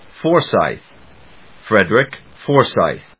音節For・syth 発音記号・読み方/fɔɚsάɪθ, [N16-A12C][N16-A12B]fɔːsάɪθ, [N16-A12C][N16-A12B]/発音を聞く